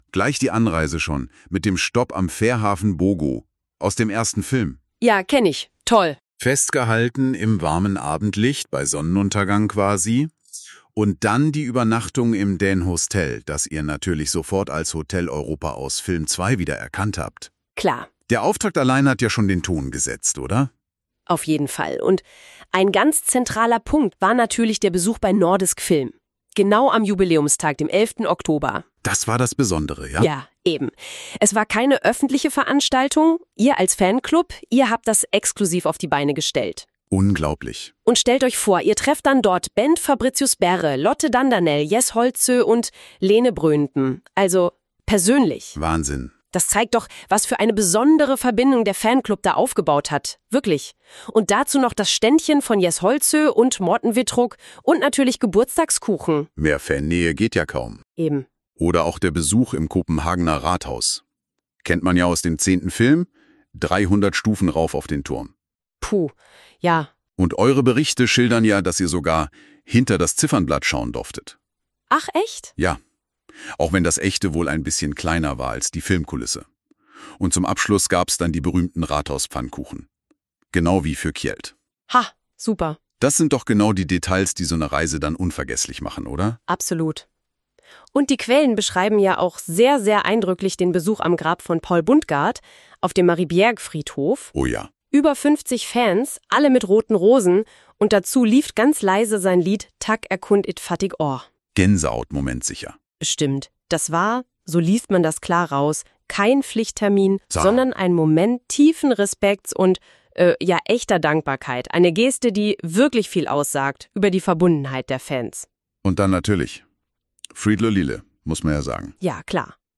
Zusammenfassung (Podcast)
MP3 (KI-generierter Audioinhalt)